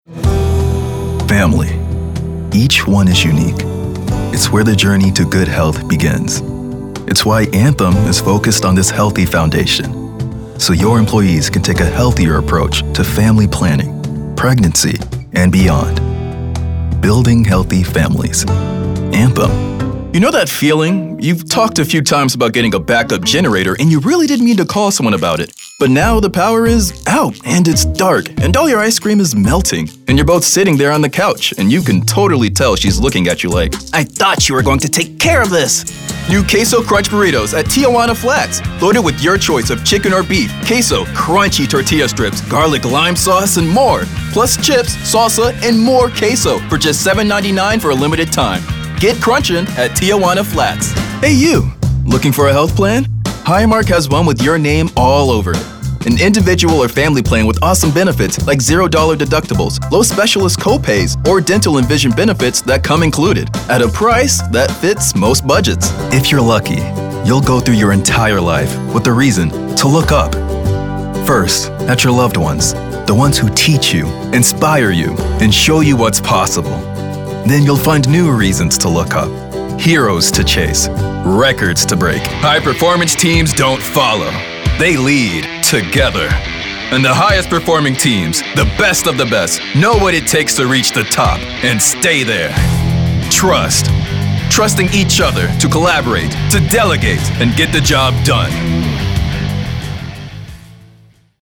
Their upbeat, friendly vibe brings scripts to life! they nail commercials, audiobooks, and animation with authentic charm.
announcer, anti-announcer, caring, concerned, confident, conversational, cool, friendly, genuine, Gravitas, guy-next-door, high-energy, inspirational, Matter of Fact, nostalgic, perky, professional, promo, smooth, storyteller, thoughtful, upbeat, warm, young adult